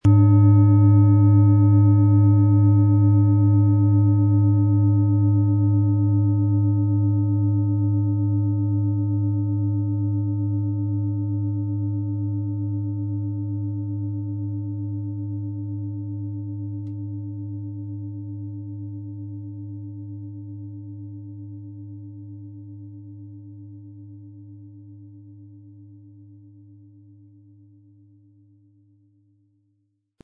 Diese tibetische Klangschale mit dem Ton von Saturn wurde von Hand gearbeitet.
• Tiefster Ton: Uranus
Unter dem Artikel-Bild finden Sie den Original-Klang dieser Schale im Audio-Player - Jetzt reinhören.
PlanetentöneSaturn & Uranus
MaterialBronze